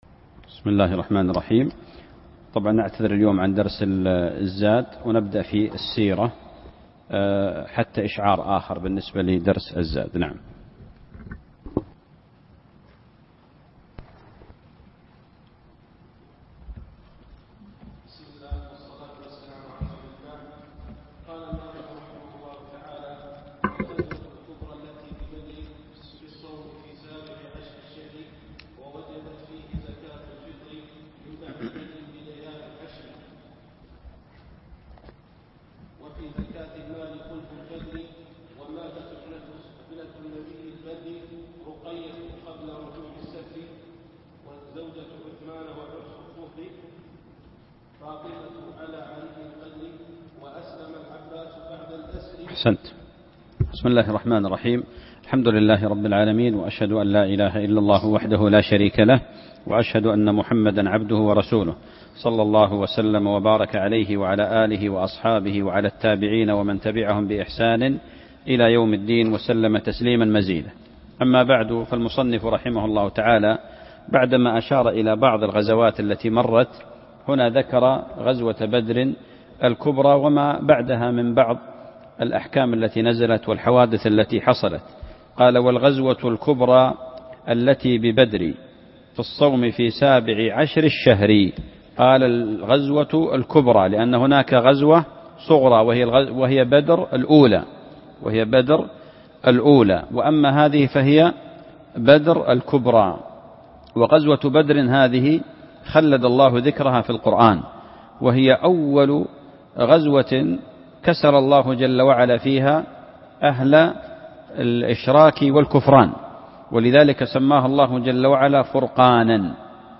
الدرس السابع